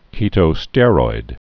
(kētō-stĕroid, -stîr-)